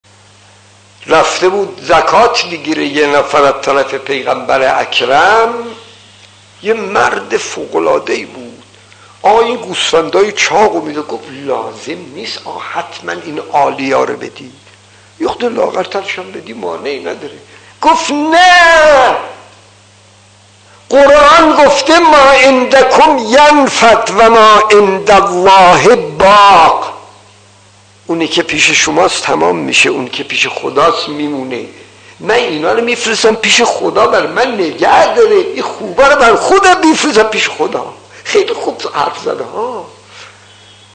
داستان 41 : زکات خطیب: استاد فلسفی مدت زمان: 00:00:40